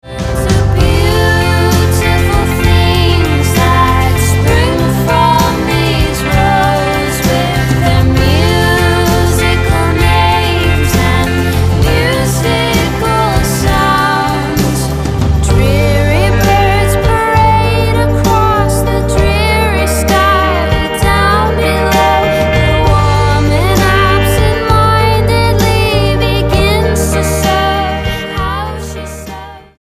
STYLE: Pop
is a mid tempo haunting tune